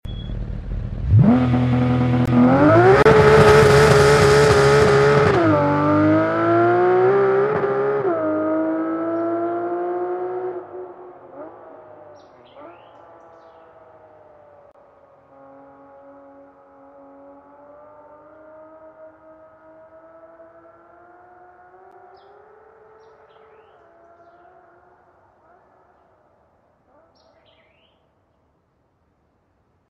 Porsche GT3 992 beamng sound effects free download